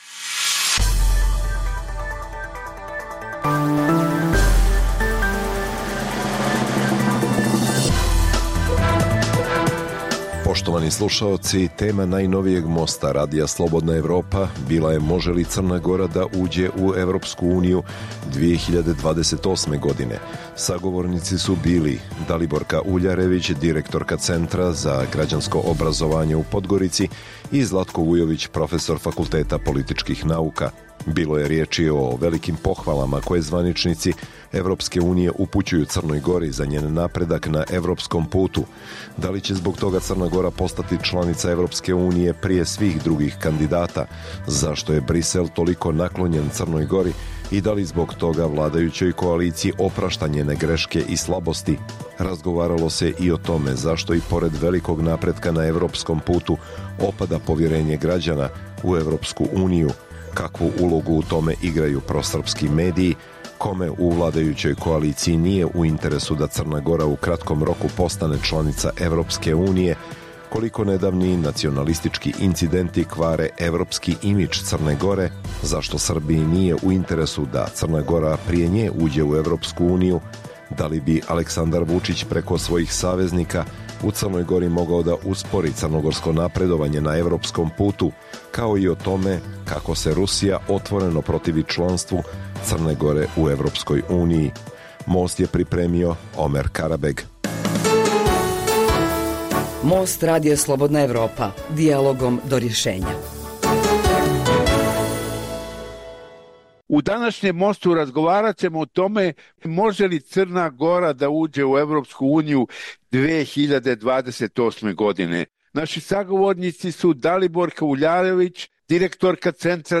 Emisija o dešavanjima u regionu (BiH, Srbija, Kosovo, Crna Gora, Hrvatska) i svijetu.